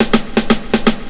FILL 2    -R.wav